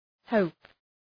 Προφορά
{həʋp}